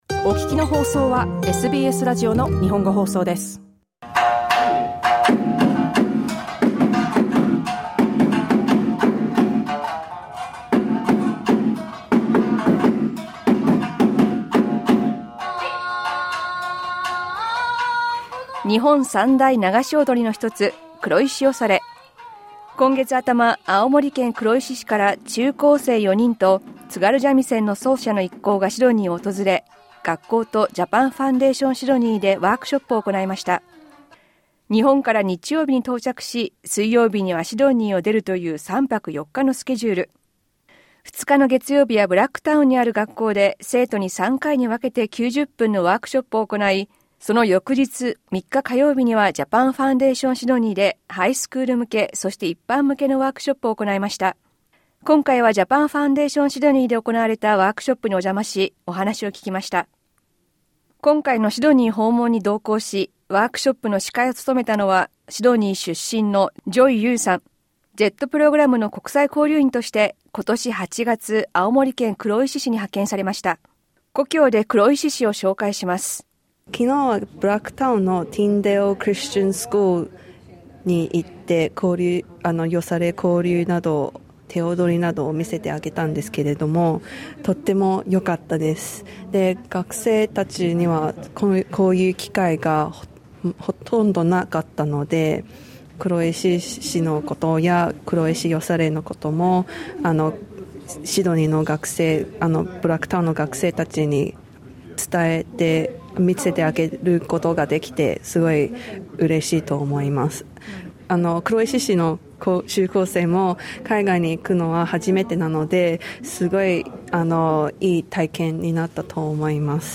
LISTEN TO 青森県の中高生4人などシドニーでワークショップ、黒石よされ踊る SBS Japanese 09:28 Japanese シドニー日本文化センターでのワークショップにお邪魔し、お話を聞きました。